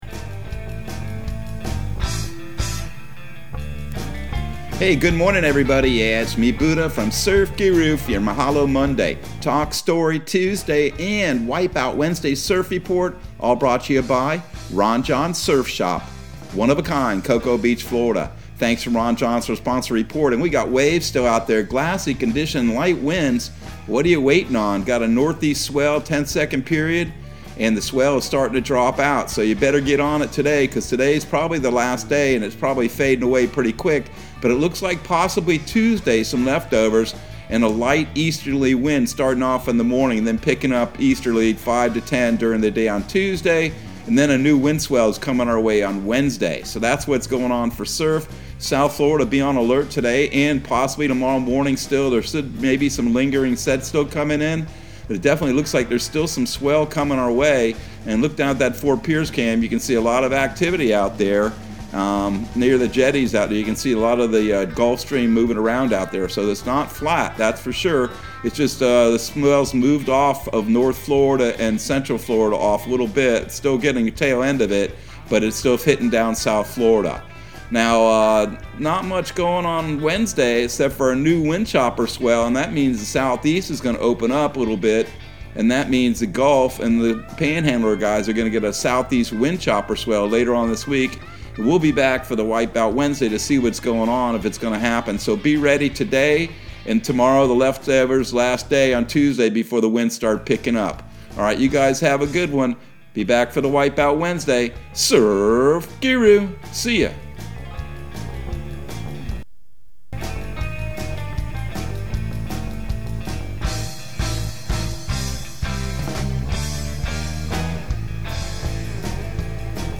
Surf Guru Surf Report and Forecast 01/31/2022 Audio surf report and surf forecast on January 31 for Central Florida and the Southeast.